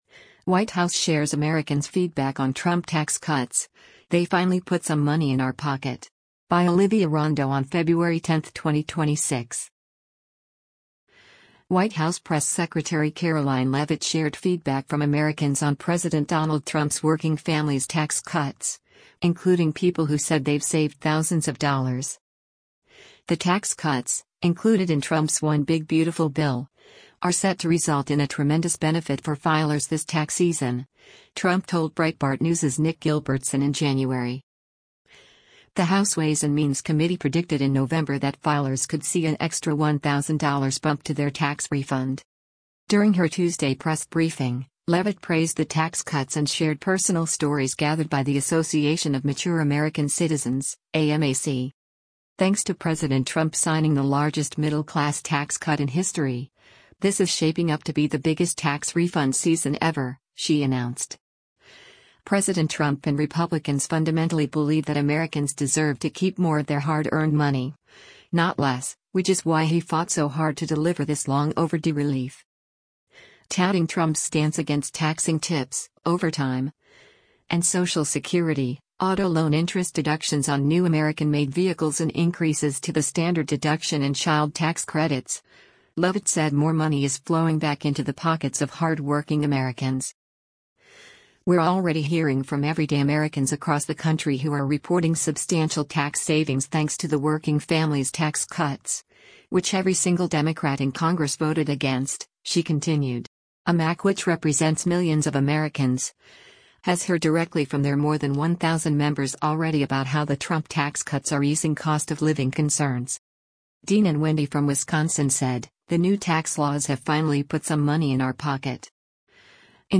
During her Tuesday press briefing, Leavitt praised the tax cuts and shared personal stories gathered by the Association of Mature American Citizens (AMAC):